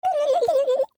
turkey-v3.ogg